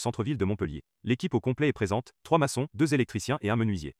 vocal-excerpt.wav